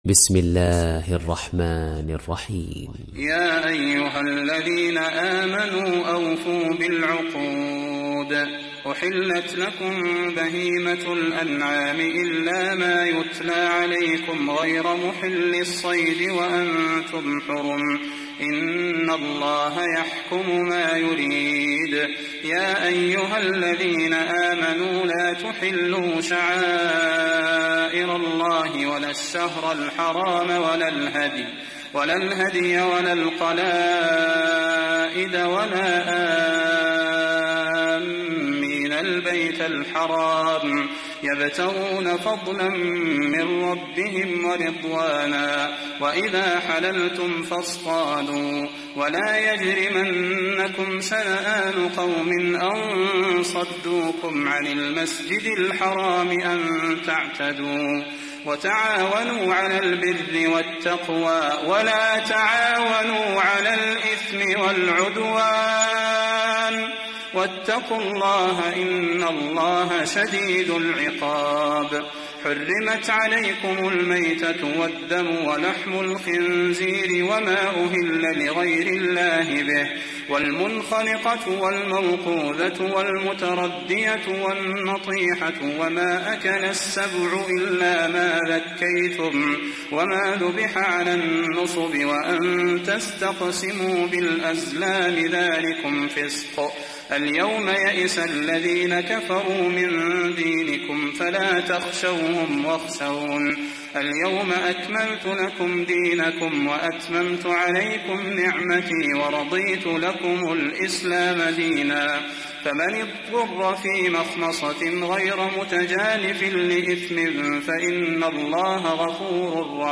تحميل سورة المائدة mp3 بصوت صلاح البدير برواية حفص عن عاصم, تحميل استماع القرآن الكريم على الجوال mp3 كاملا بروابط مباشرة وسريعة